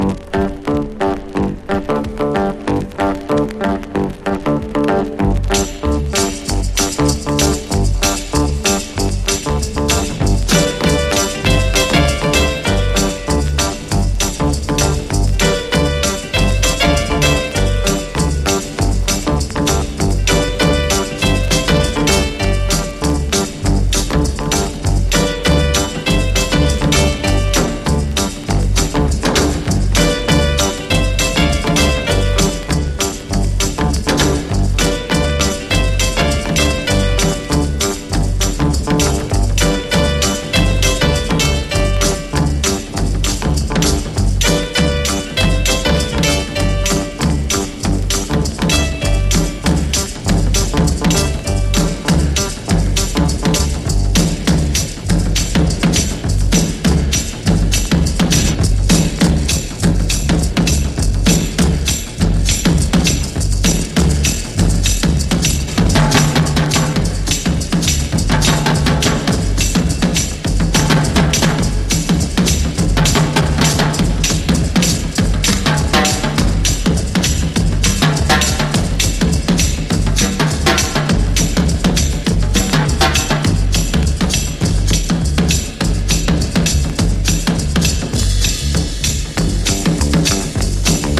ファンキー・ドラム・インストロ・クラシック！